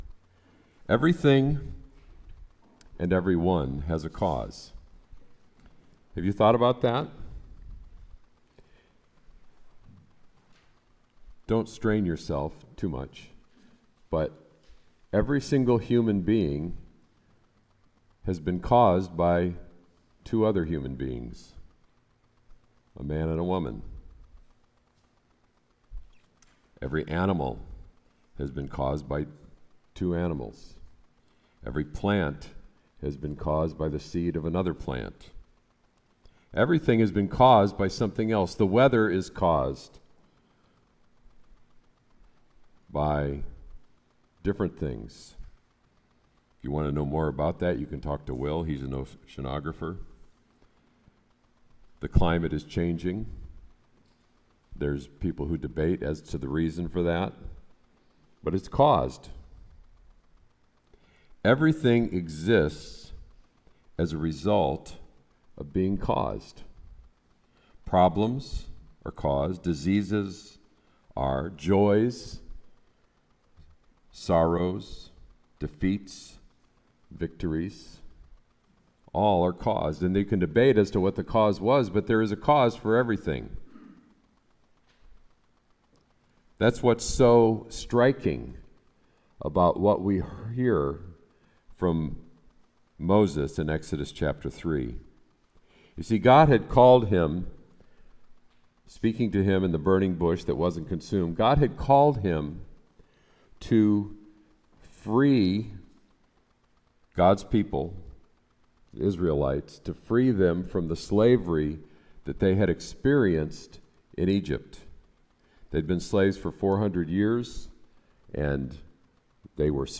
Sermon “I AM HE”